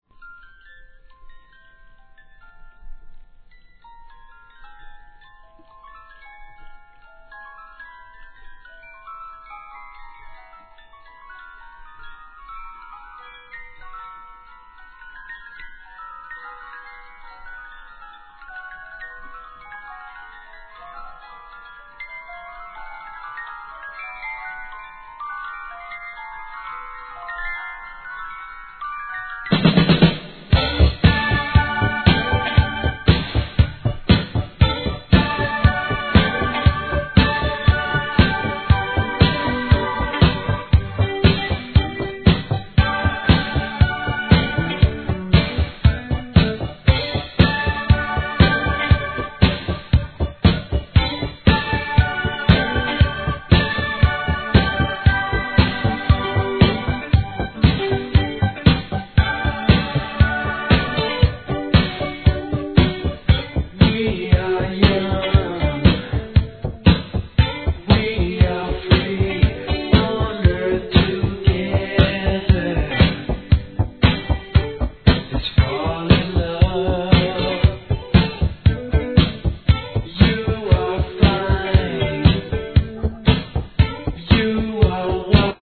SOUL/FUNK/etc...
王道なFUNKを聴かせてくれる名盤!